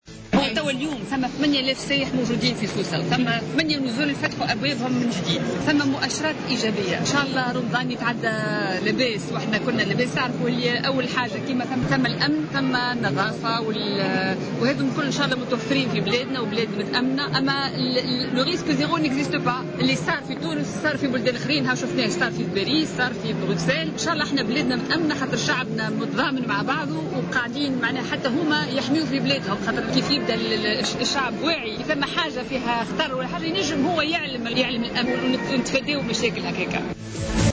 Dans une déclaration accordée à Jawhara Fm en marge de l'ouverture du Salon International du Tourisme, la ministre a indiqué que 8 000 touristes se trouvent actuellement à Sousse et que 8 hôtels ont repris leurs activités.